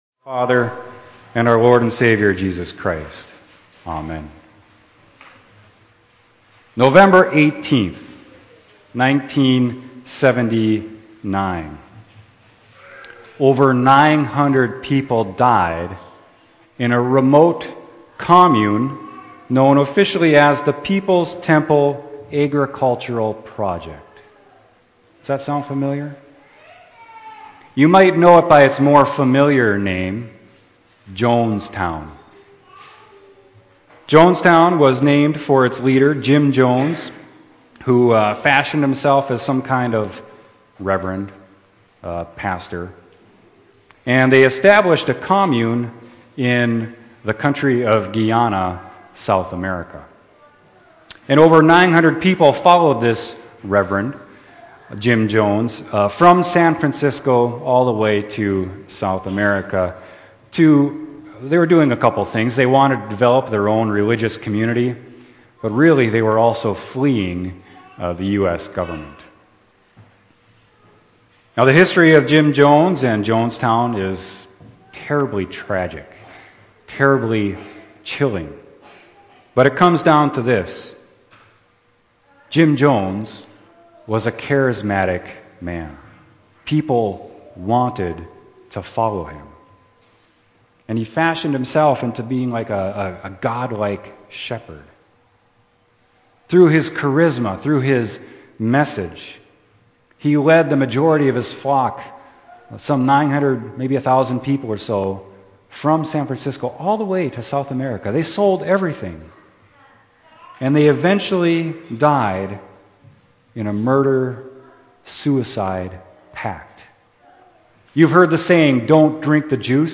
Sermon: Good Shepherd